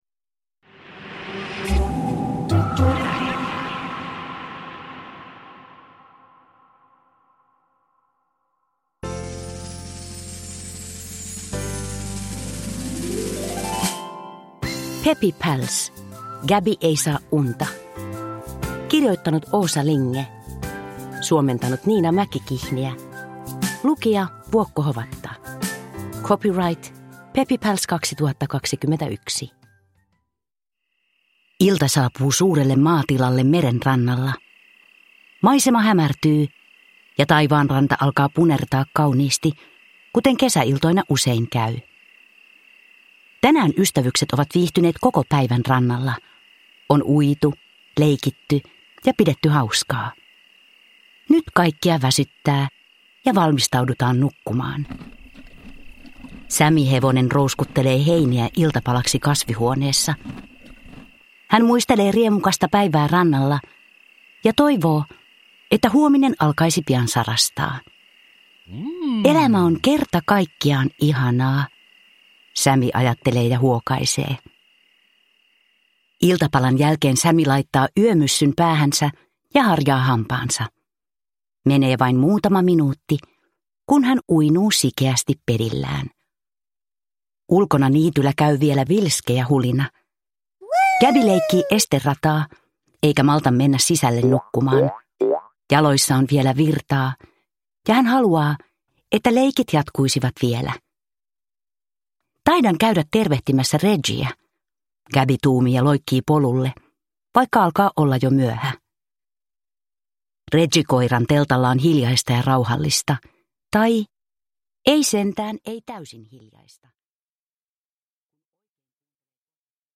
Peppy Pals: Gabby ei saa unta – Ljudbok – Laddas ner